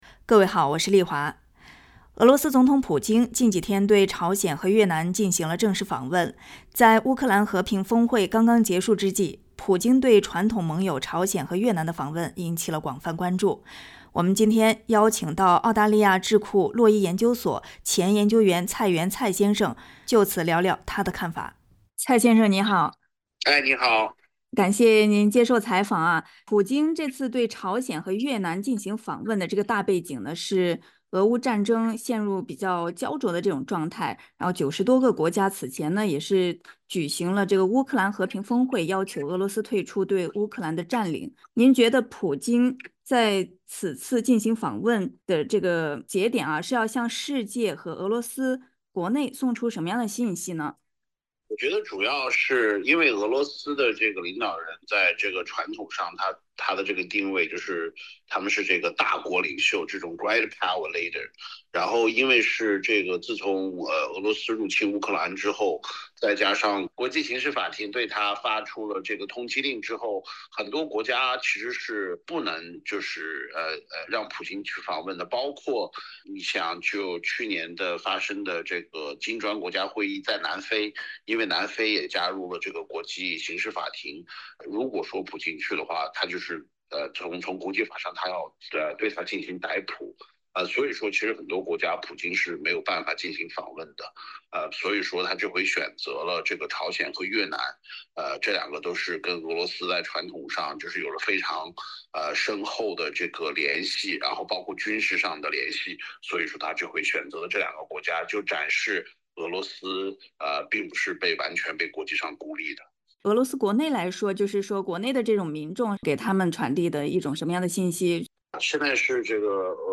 普京去了朝鲜接着又访越南，背后有哪些用意？点击上方音频收听采访。